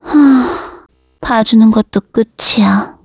Gremory-Vox_Skill4_kr.wav